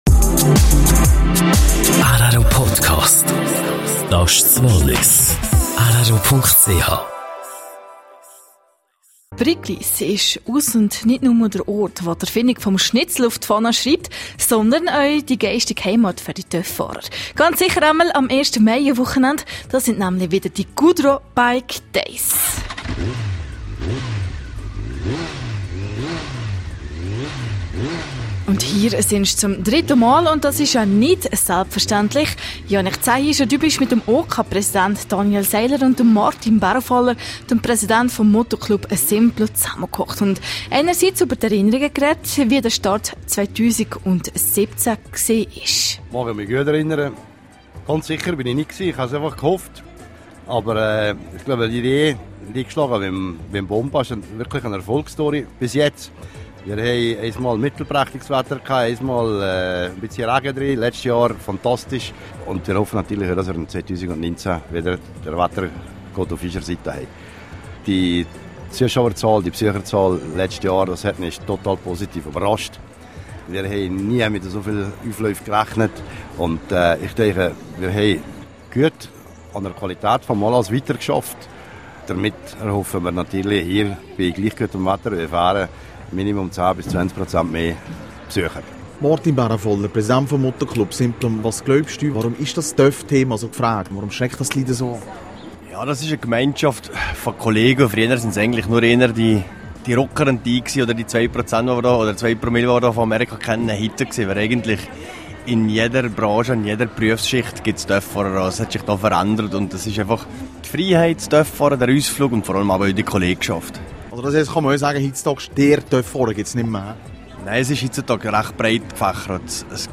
17:00 Uhr Nachrichten (4.42MB)